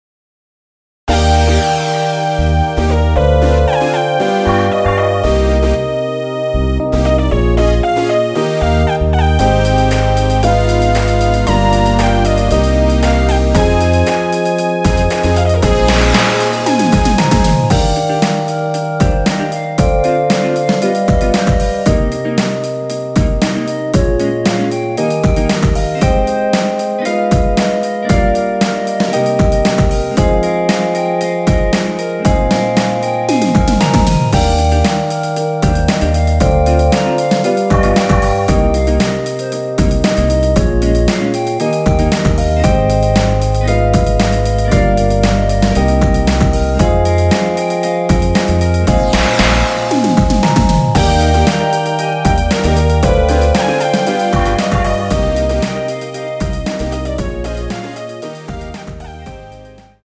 원키에서(+4)올린 멜로디 포함된 MR입니다.
Eb
앞부분30초, 뒷부분30초씩 편집해서 올려 드리고 있습니다.
(멜로디 MR)은 가이드 멜로디가 포함된 MR 입니다.